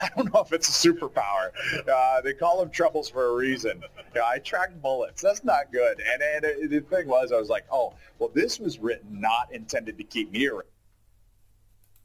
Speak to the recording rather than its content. Noisy Speech